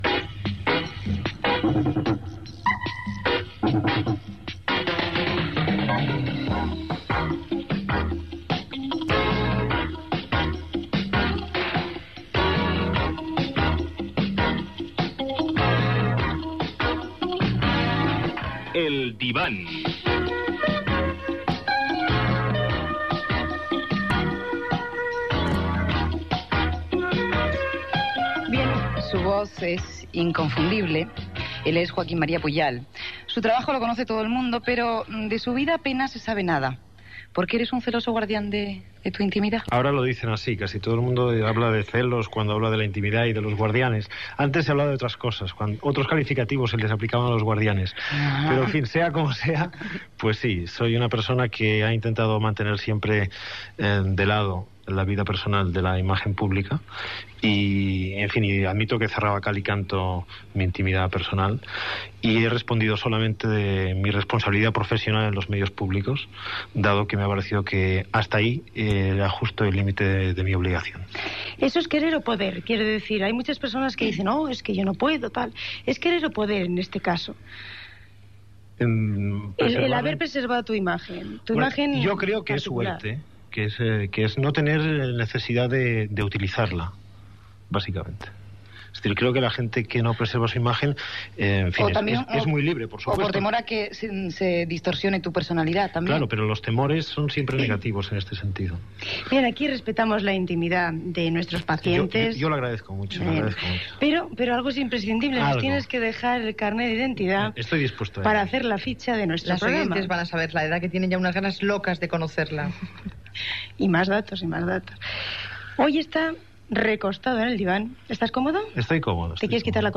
Careta del programa, inici de l'entrevista al presentador Joaquim Maria Puyal, tot parlant de la seva vida personal.
Entreteniment